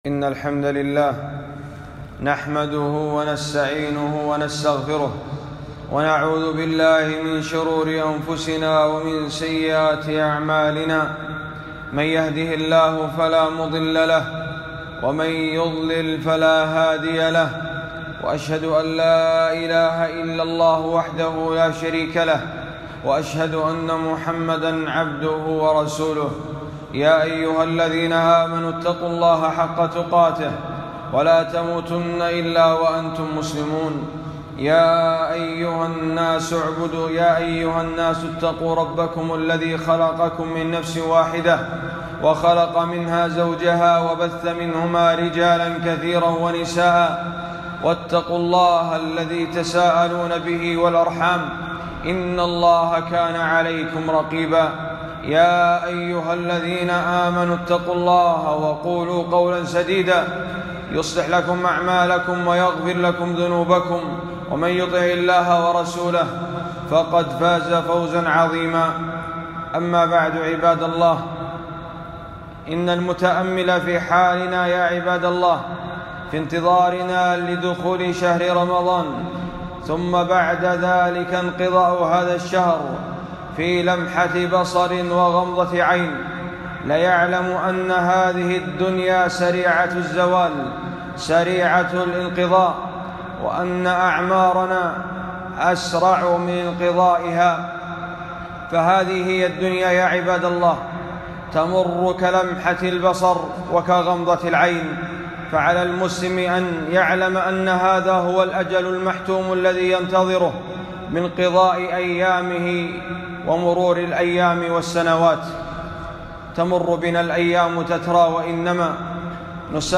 خطبة - ماذا تعلمنا من مدرسة رمضان